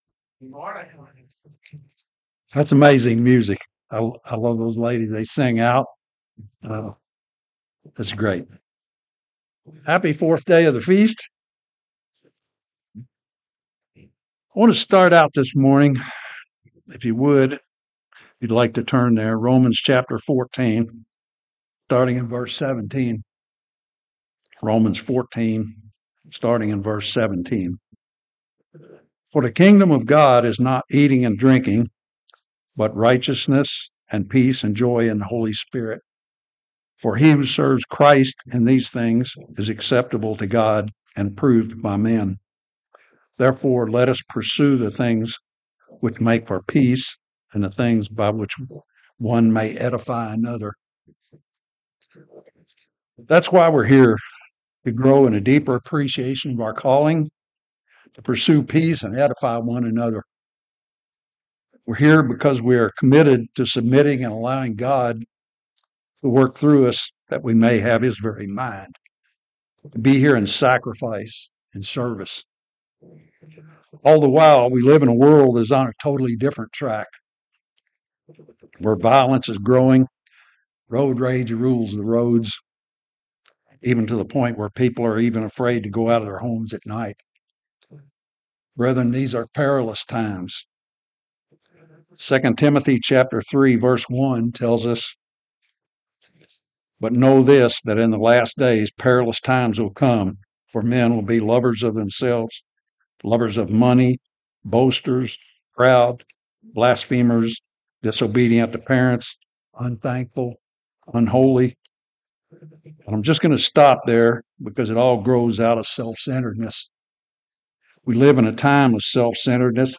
This sermon was given at the Cincinnati, Ohio 2024 Feast site.